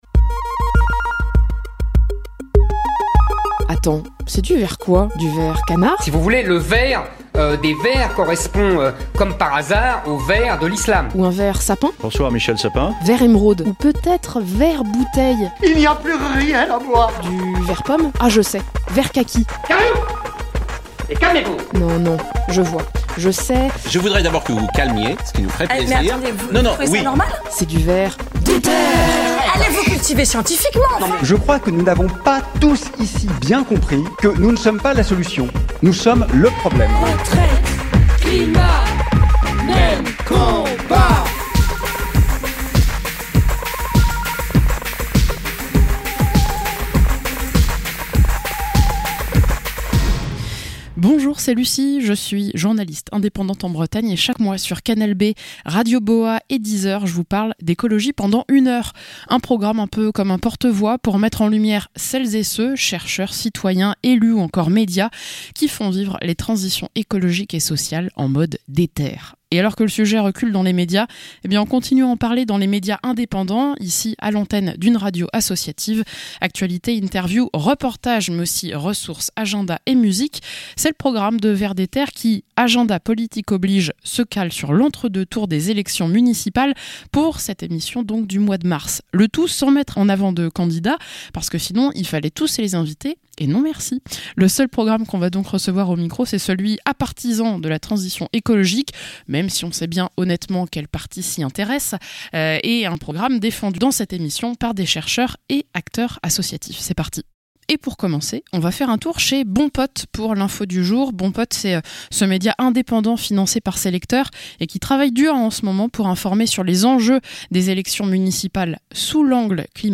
- Interview avec 2 membres d'un collectif de chercheur·euses à l'Université Marie-et-Louis Pasteur à Besançon